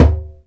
DJEMBE 2A.WAV